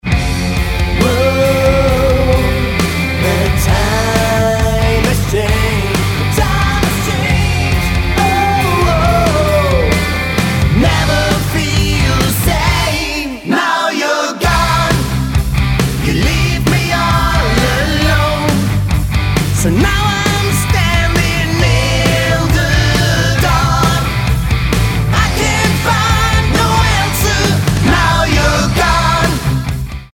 Lead Vocals
Bass, Vocals
Gitarre, Vocals
Drums, Vocals